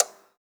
pgs/Assets/Audio/Miscellaneous/Clock/clock_tick_02.wav
clock_tick_02.wav